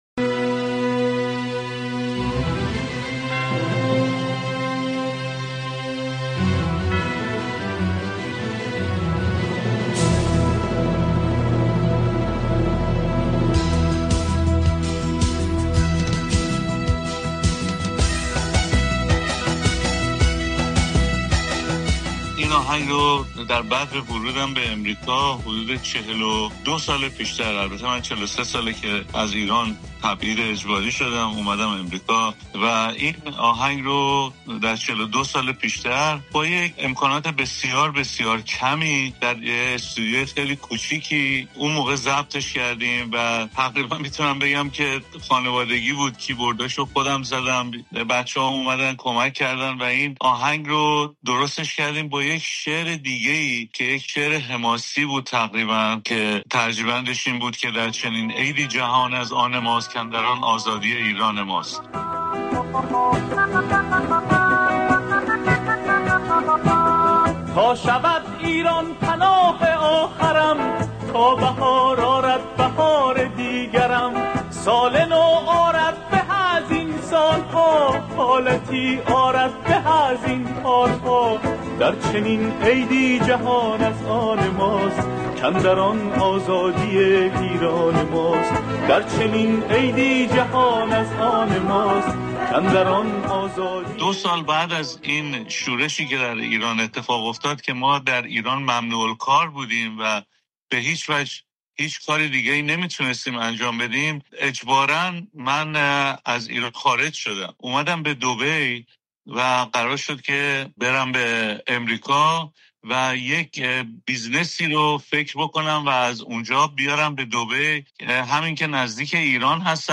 گفت‌وگوی نوروزی رادیو فردا